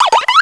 pokeemerald / sound / direct_sound_samples / cries / tirtouga.aif
tirtouga.aif